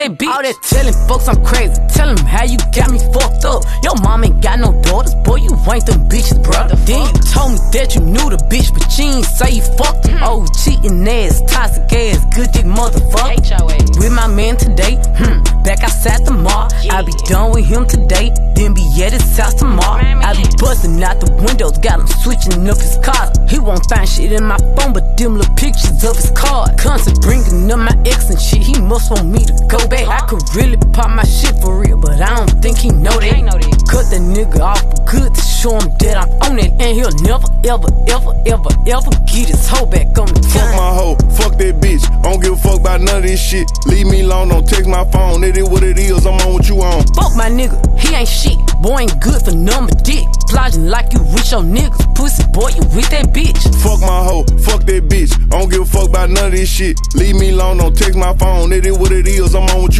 Sigh Sound Effects Free Download
sigh sound effects free download